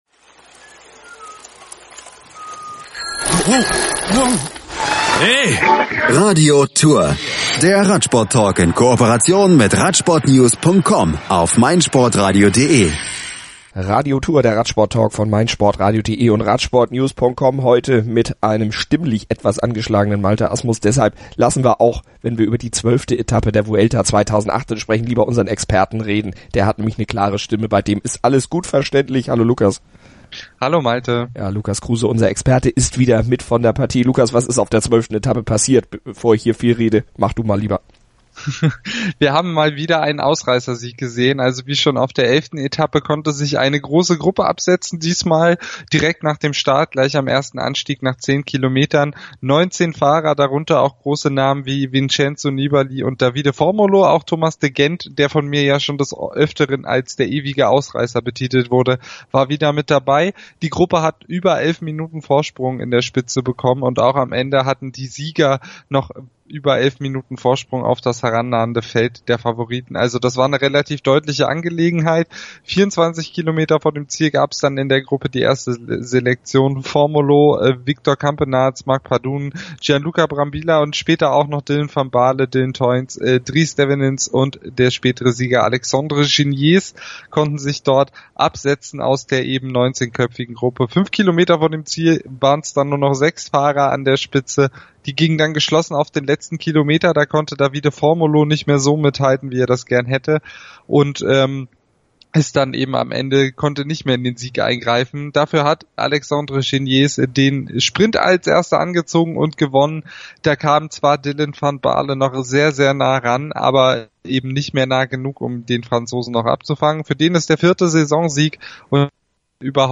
stimmlich angeschlagene